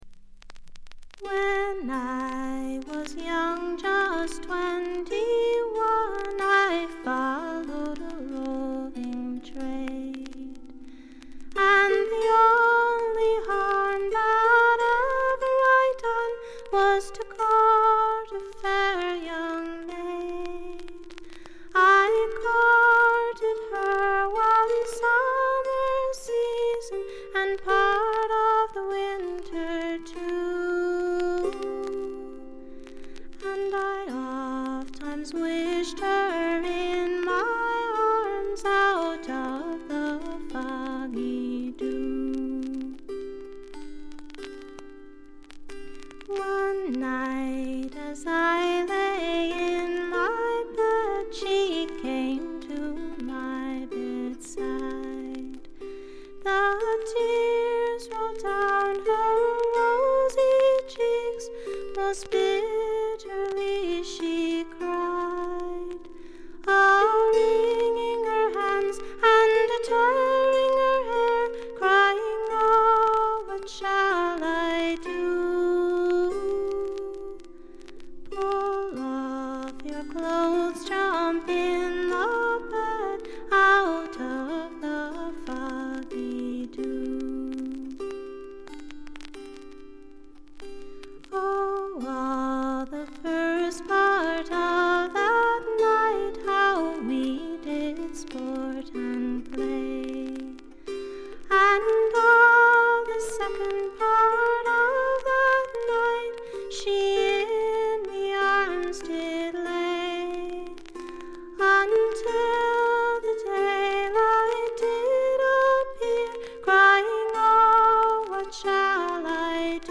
内容は自身の弾き語りを中心にした静謐でピュアで美しいフォーク・アルバムです。
試聴曲は現品からの取り込み音源です。
Vocals, Guita, Psalteryr